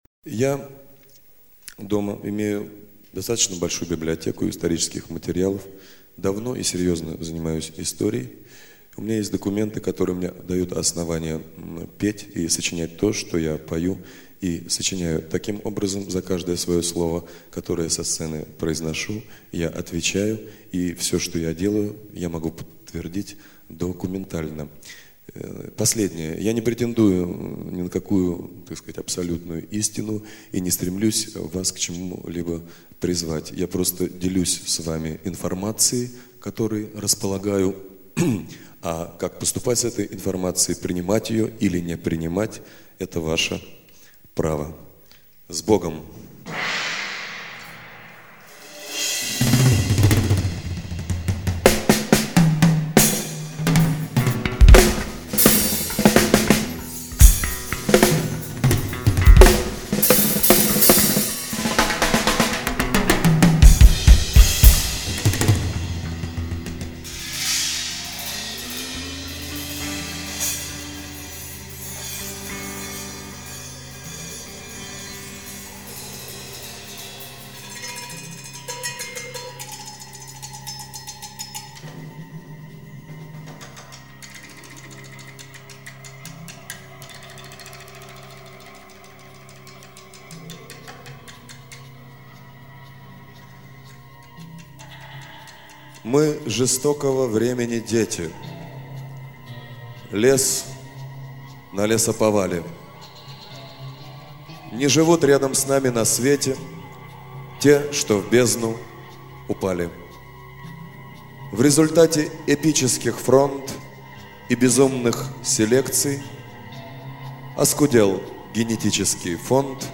3. «Игорь Тальков – Правда о России (декламация, 1991 г.)» /
Talkov-Pravda-o-Rossii-deklamaciya-1991-g.-stih-club-ru.mp3